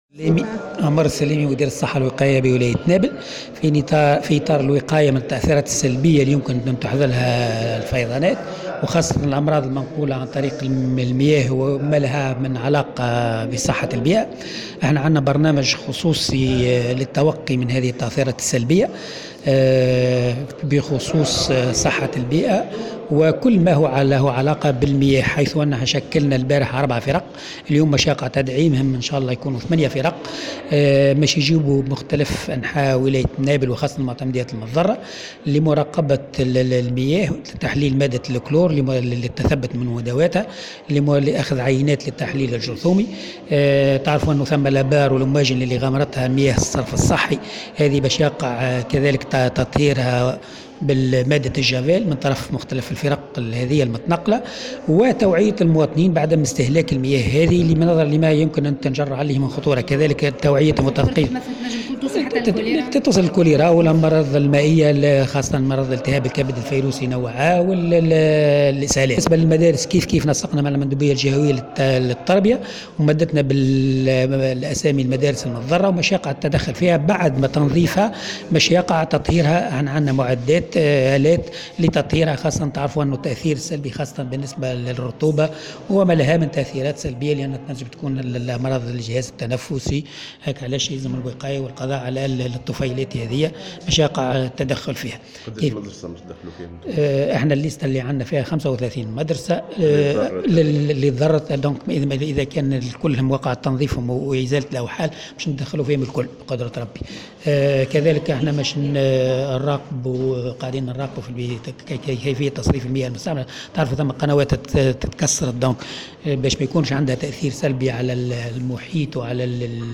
وشدّد في تصريح لمراسلة "الجوهرة اف أم" على ضرورة عدم شرب مياه الآبار والمواجل بسبب تلوّثها بمياه الصرف الصحي منبها إلى مخاطر انتشار أمراض منقولة مثل الكوليرا والتهاب الكبد الفيروسي أ. واعلن أنه تم تشكيل 4 فرق صحية لمراقبة المياه والقيام بعمليات تحليل جرثومي للمياه وتطهيرها بمادة الجفال، مؤكدا توعية المواطنين بعدم استهلاك هذه المياه.